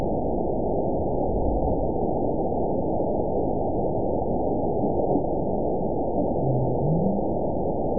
event 920240 date 03/08/24 time 17:23:29 GMT (1 year, 2 months ago) score 9.39 location TSS-AB02 detected by nrw target species NRW annotations +NRW Spectrogram: Frequency (kHz) vs. Time (s) audio not available .wav